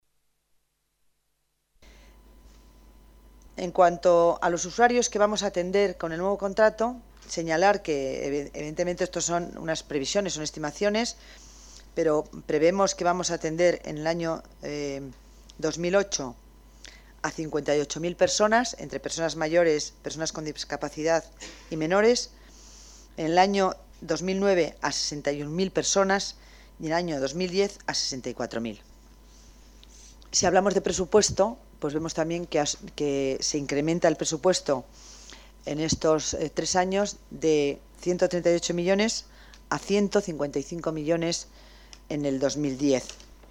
Nueva ventana:Declaraciones de la delegada de Servicios Sociales, Concepción Dancausa: más presupuesto y profesionales para la ayuda a domicilio